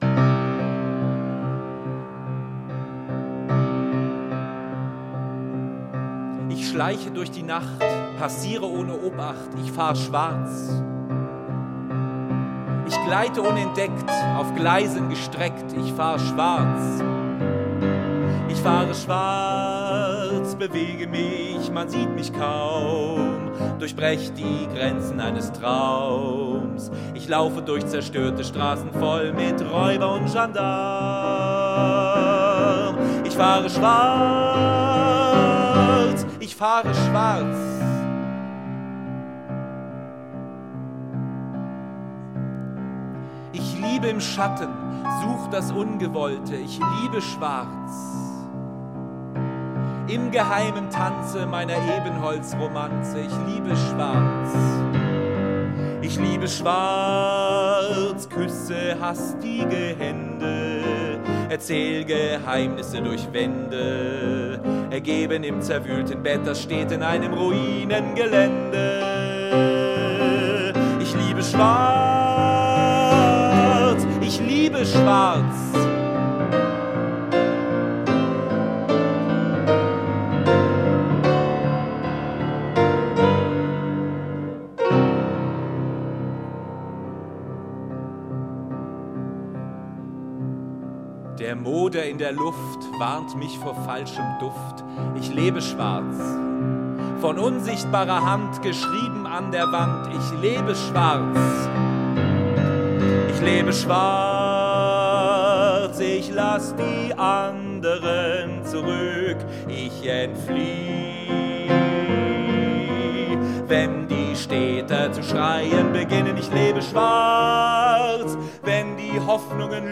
der ihn auch am Klavier begleitet.